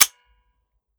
5.56 M4 Rifle - Dry Trigger 002.wav